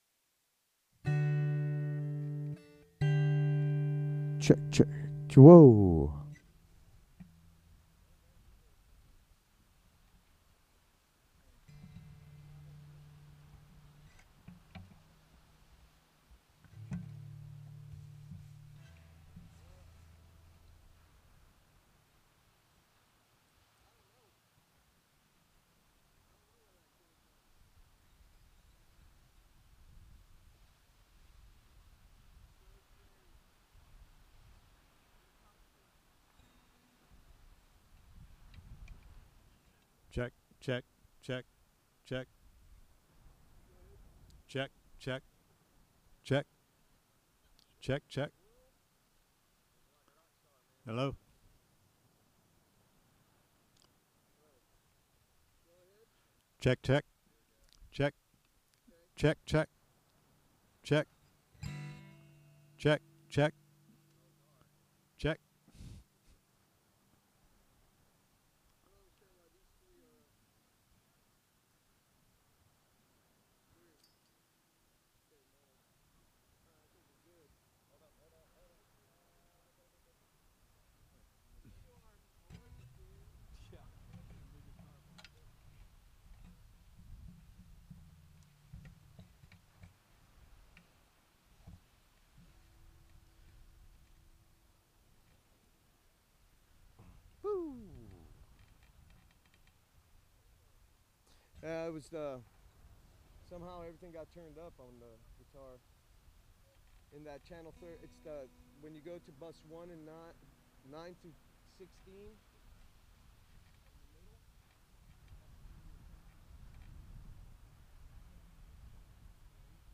SERMON DESCRIPTION This sermon is a tender call to return to the Lord, who wounds to heal and disciplines in love.